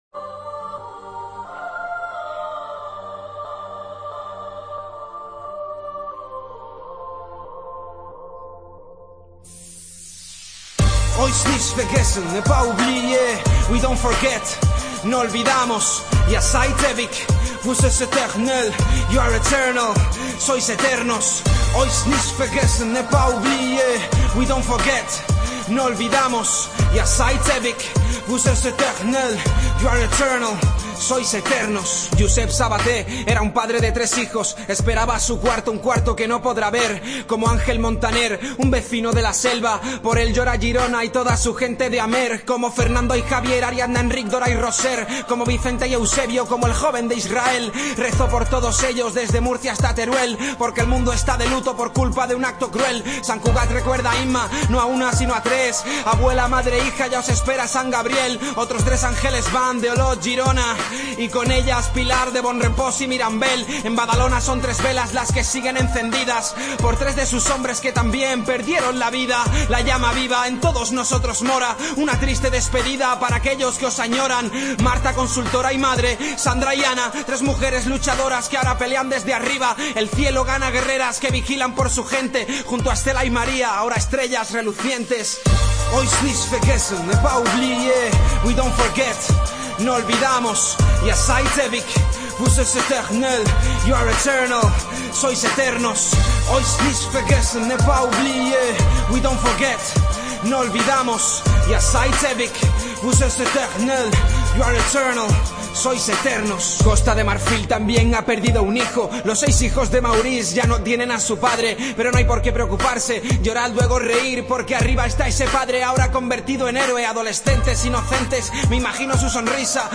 El rap
Tiempo de Juego a ritmo de rap